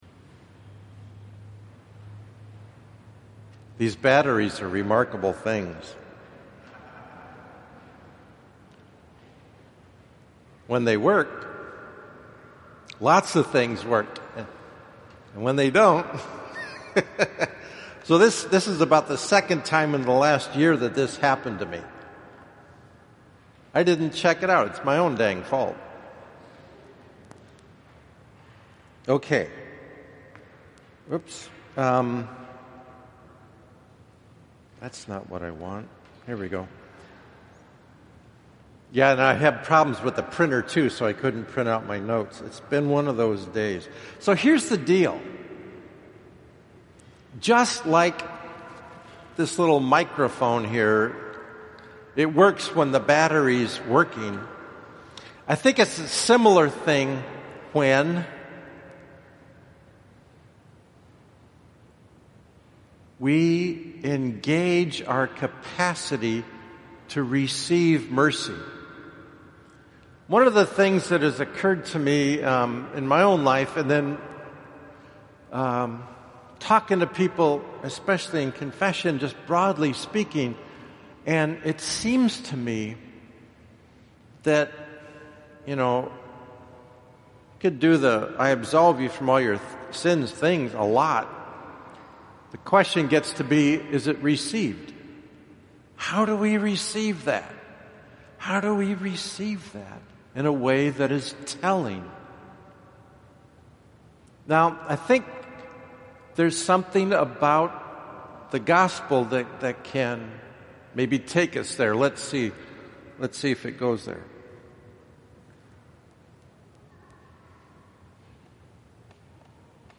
Homily for the 4th Sunday of Lent